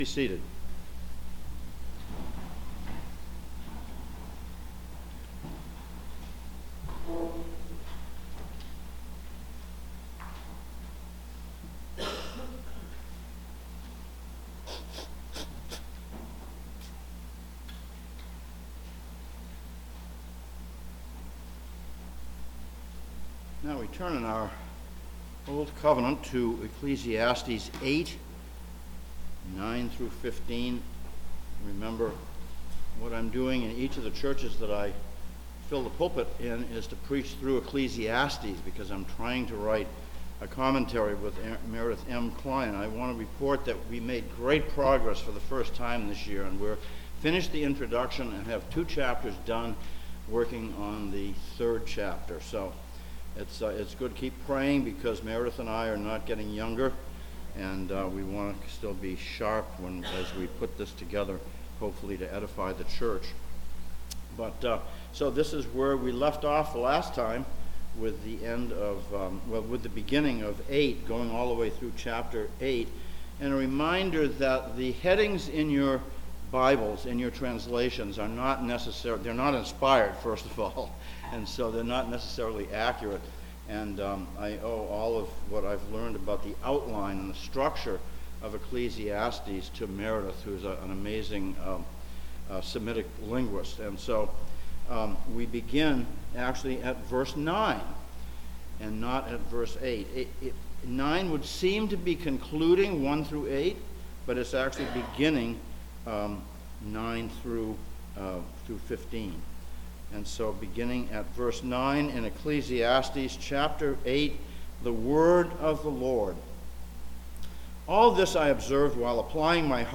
A Sermon from Ecclesiastes 8:9-15
Service Type: Sunday Morning